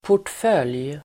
Uttal: [por_tf'öl:j]